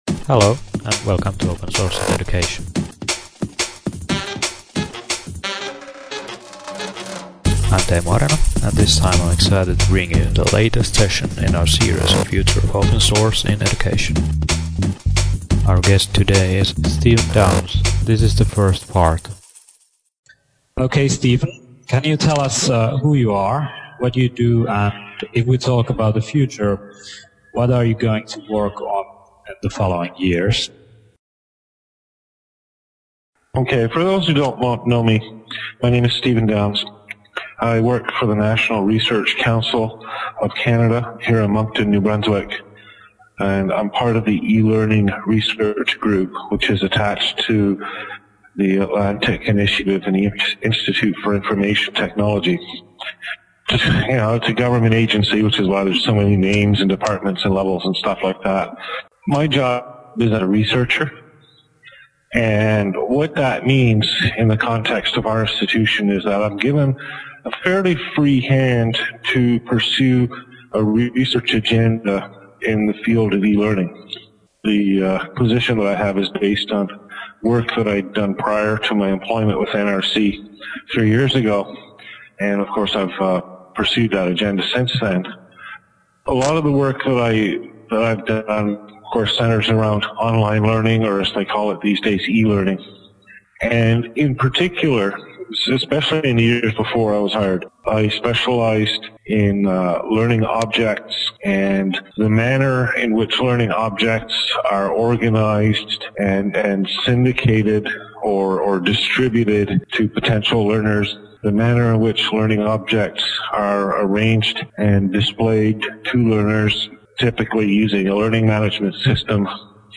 FLOSSE continues with its series of interviews, releasing part 1 of its interview with me today.